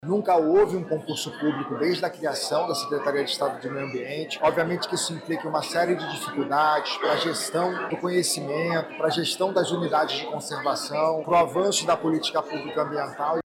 O secretário estadual de Meio Ambiente, Eduardo Taveira, explica que o processo enfrenta dificuldades visto que nunca foi feito um concurso para esta área, no Estado.